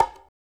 17 CONGA.wav